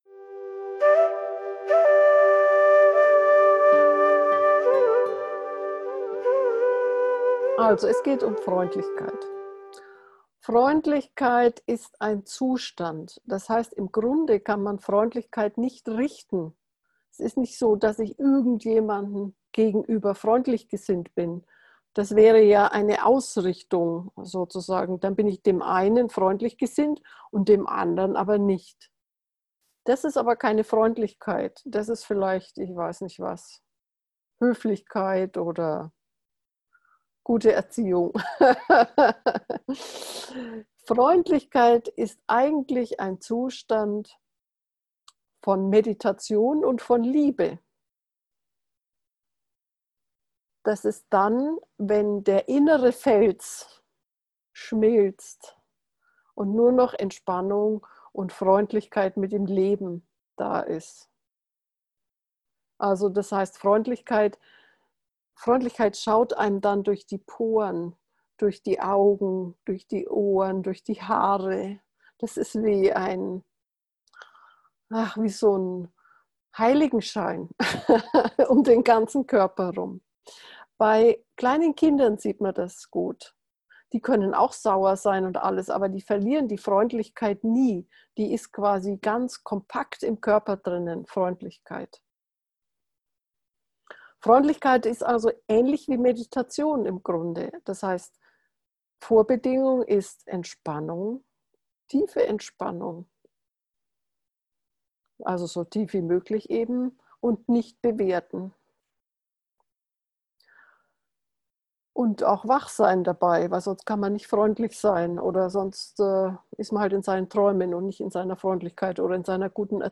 Herzmeditationen